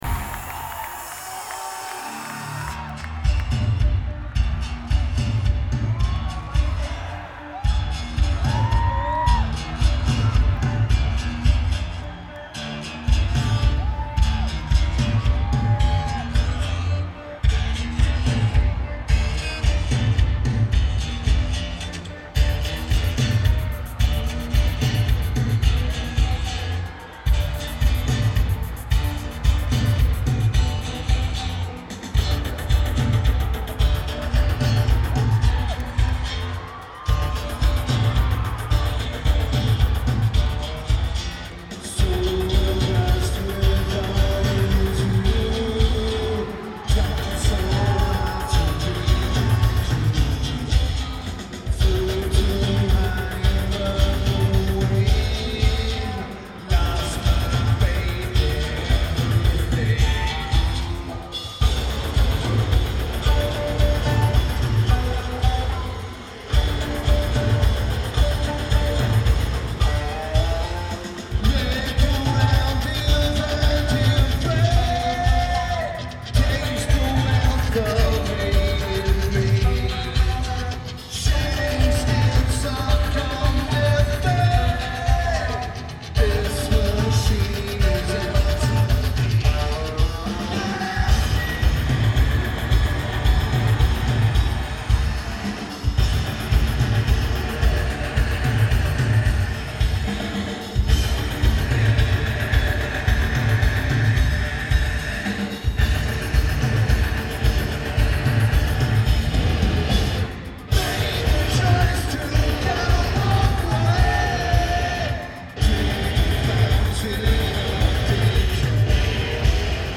Hartford Civic Center
Keyboards/Programming
Bass/Guitar
Drums
Guitar
Vocals/Guitar/Keyboards